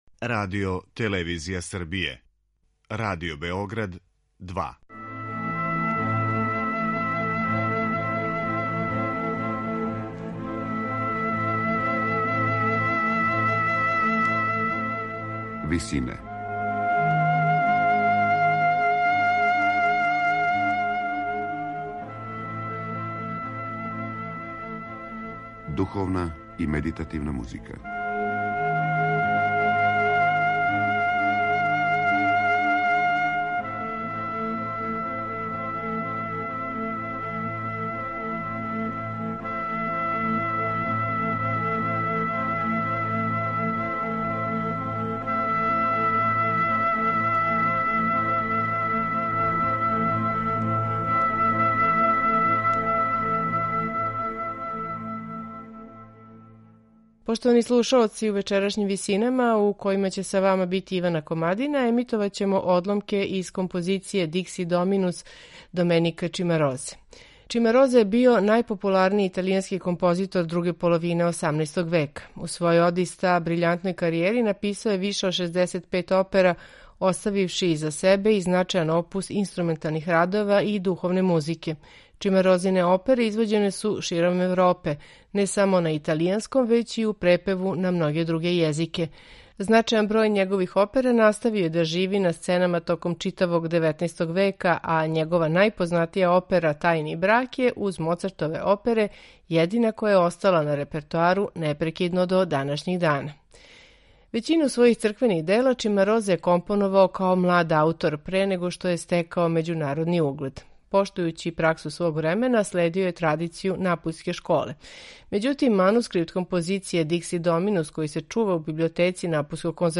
Следећи век и по дугу традицију духовне музике, аутор је овде у музичкој обради стихова користио наизменично низање солистичких и хорских одсека, као и обједињавање целокупног извођачког састава у уводном и завршном ставу.
сопран
мецо-сопран
тенор